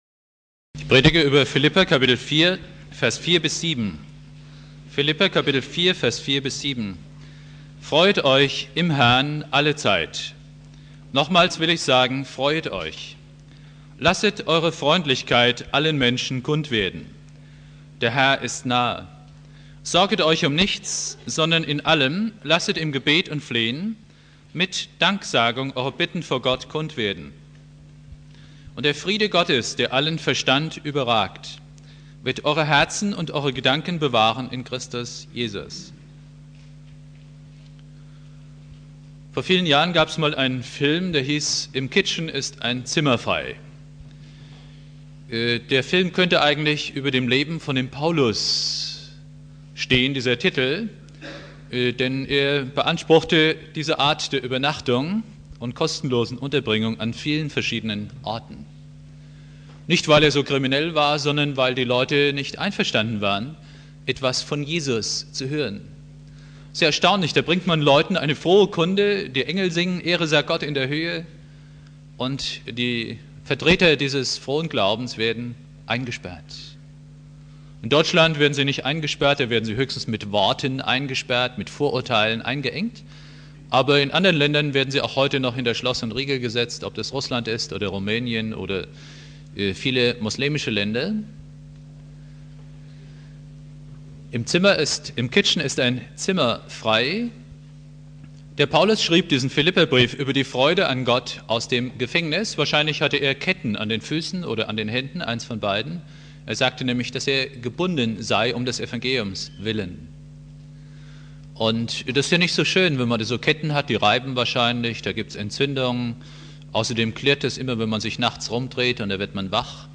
Predigt
4.Advent